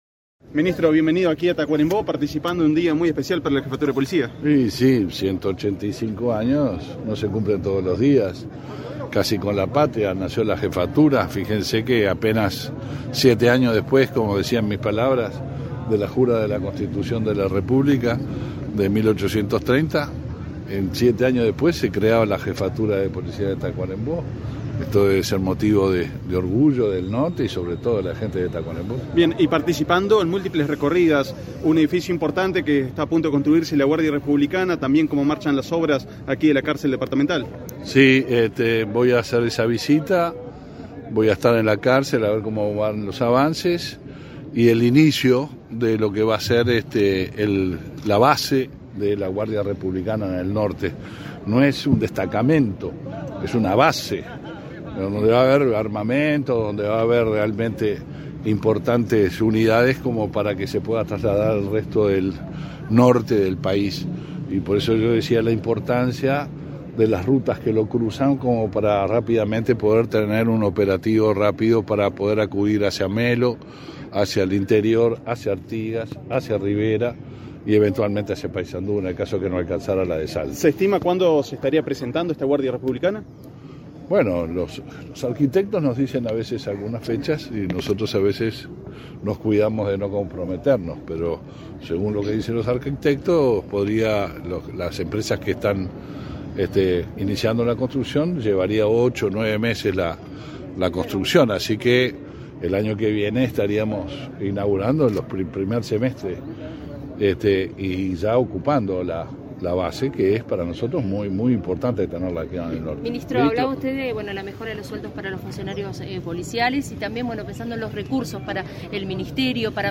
Declaraciones a la prensa del ministro del Interior, Luis Alberto Heber
Tras participar en el 185.° aniversario de la Jefatura de Tacuarembó, este 12 de agosto, el ministro Heber efectuó declaraciones a al prensa.